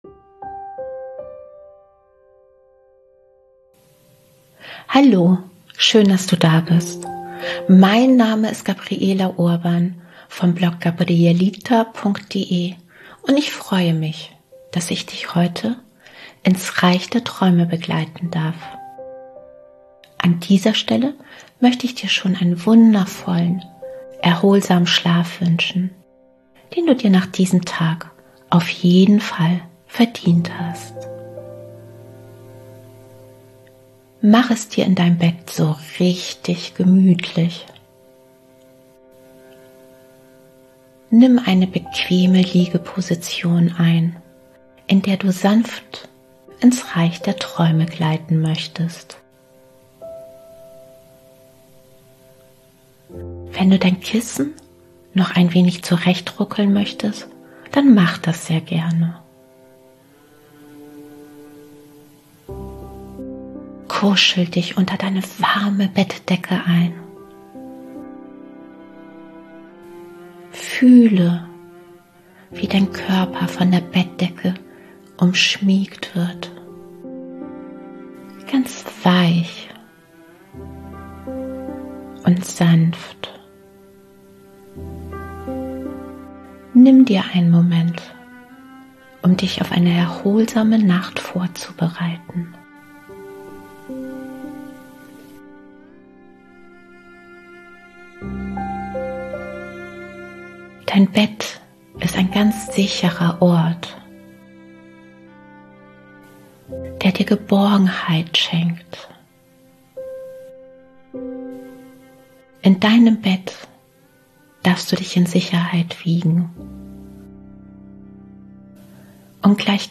Denn diese 20-minütige Einschlafmeditation unterstützt dich vor allem dabei, deine Gedanken zur Ruhe kommen zu lassen, ganz natürlich zu atmen und deinen Körper zu entspannen. Außerdem fördert die geführte Meditation einen festen, erholsamen Schlaf, sodass dein Körper sich nachts regenerieren kann – und du morgens erholt und erfrischt aufwachst.